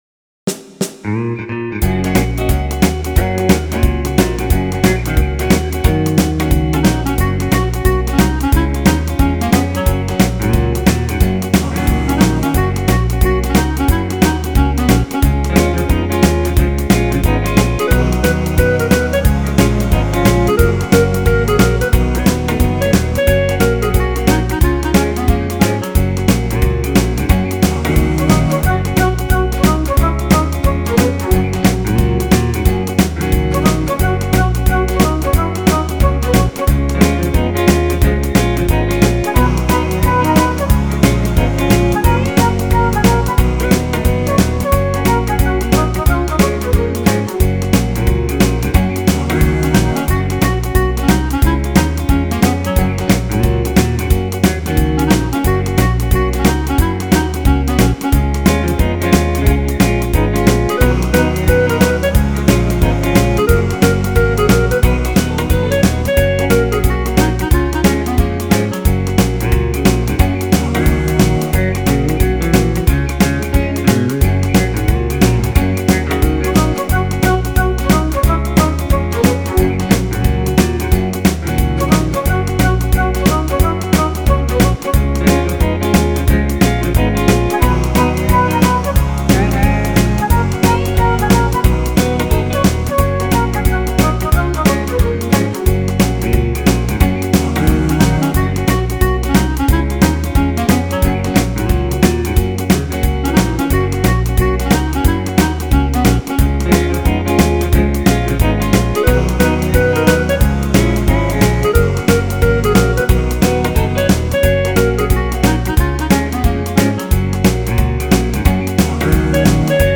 thWETP5JLM  Download Instrumental
[It’s on the Country List]